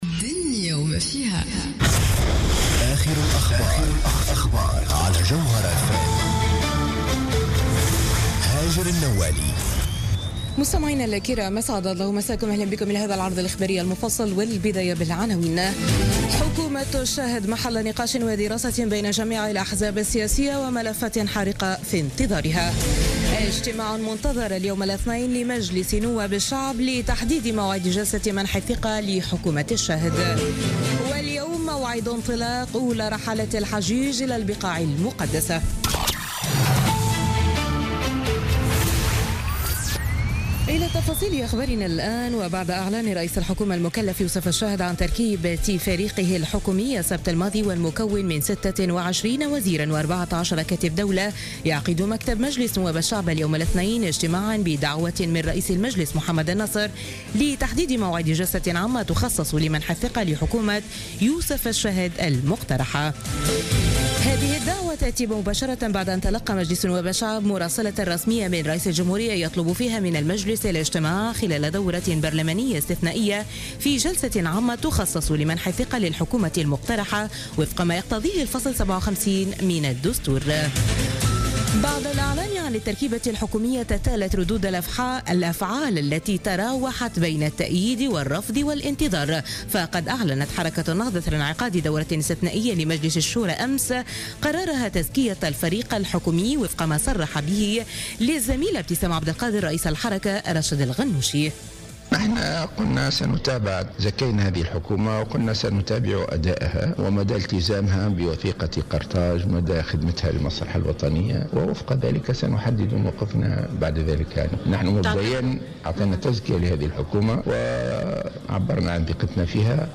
نشرة أخبار منتصف الليل ليوم الاثنين 22 أوت 2016